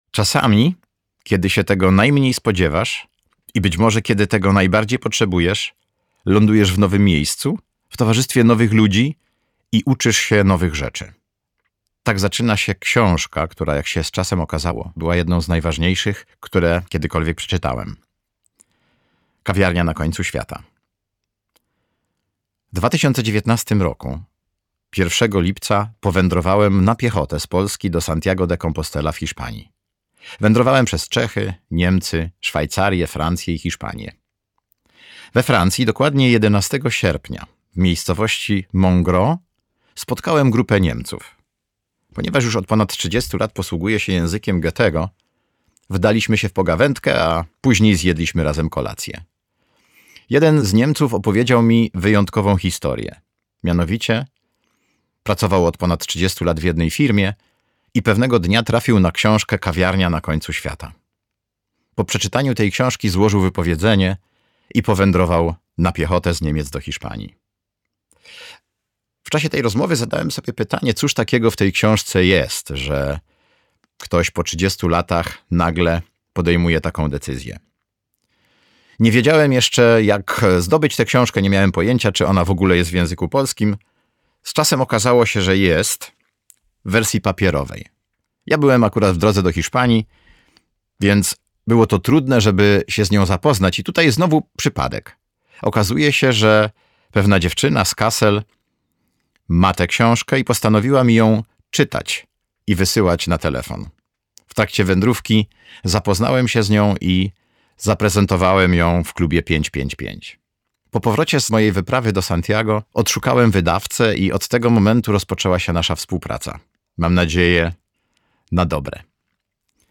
Kawiarnia na końcu świata - Strelecky John - audiobook + książka